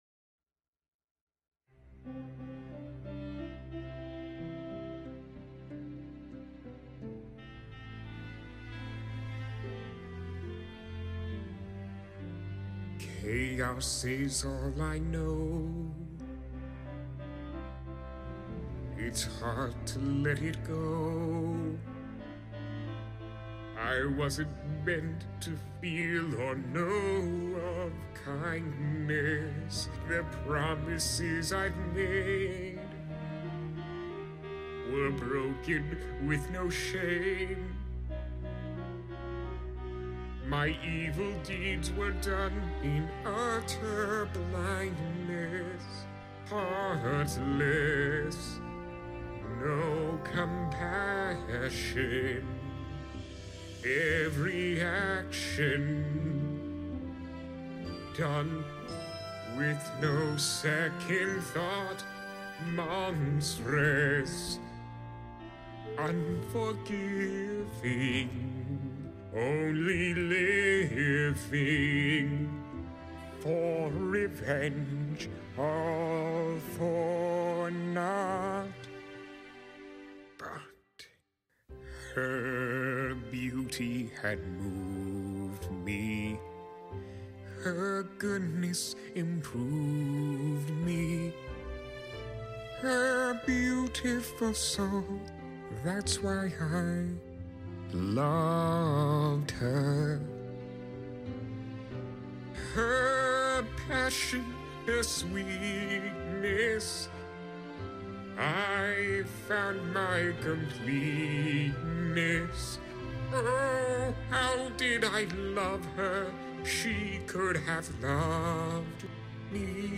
This is a cover of the deleted song